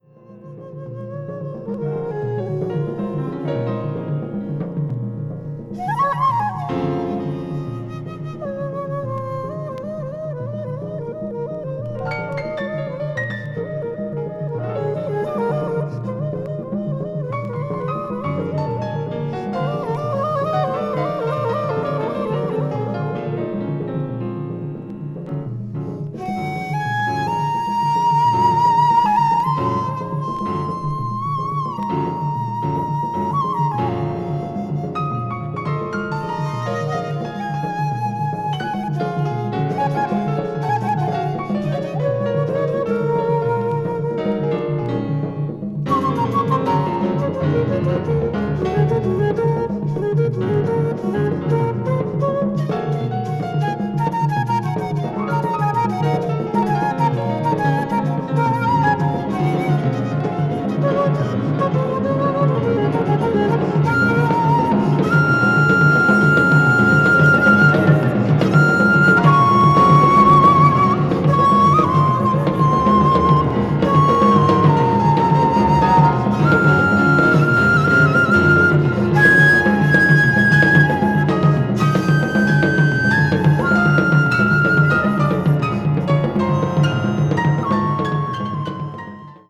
bamboo flute player
the great Japanese drummer and percussionist
one of Japan's leading jazz pianists